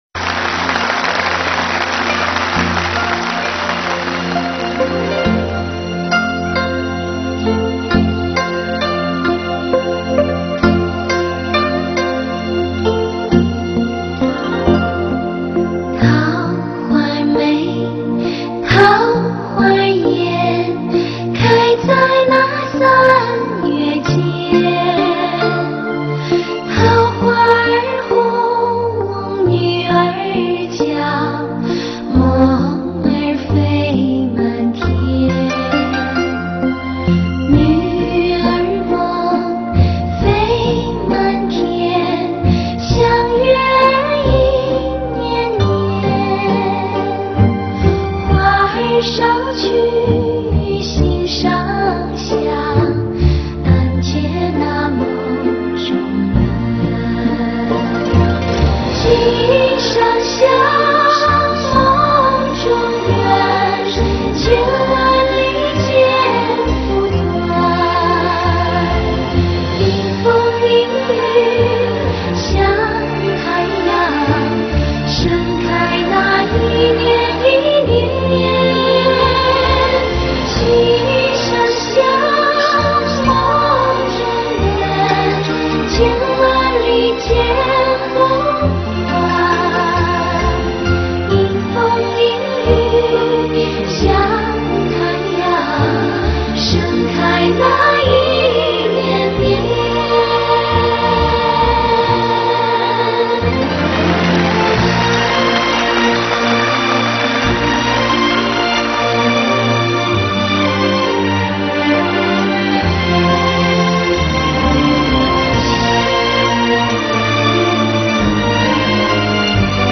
下面这个版本是芊曲组合在印青作品音乐会上演唱的